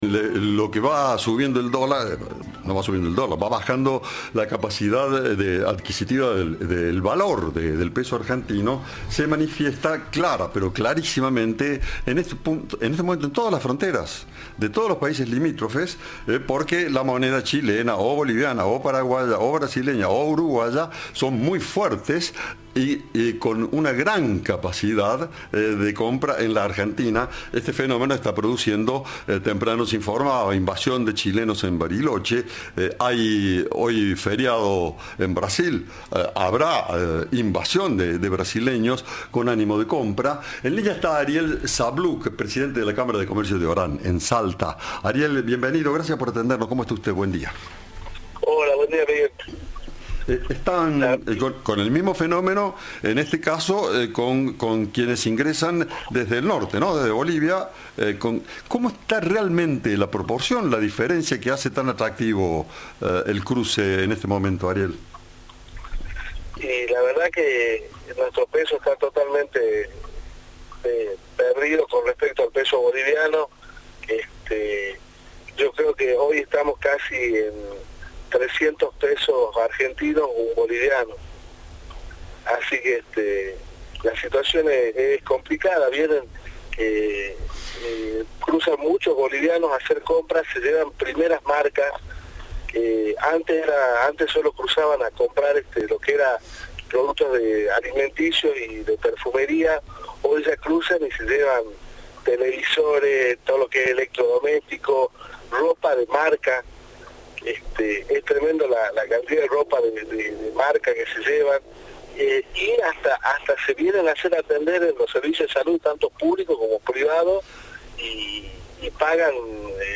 Informe
Entrevista